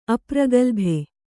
♪ apragalbhe